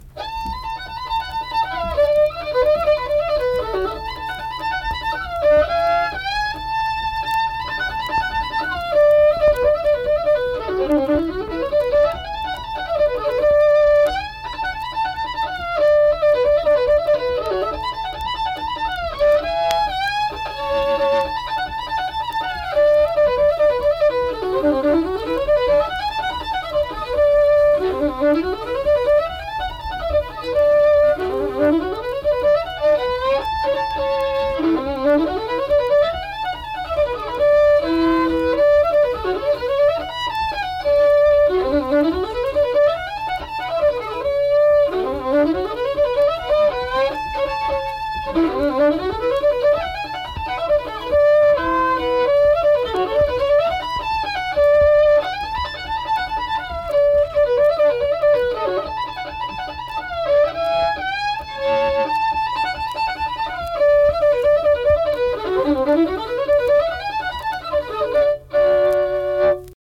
Unaccompanied fiddle music and accompanied (guitar) vocal music performance
Verse-refrain 2(2).
Instrumental Music
Fiddle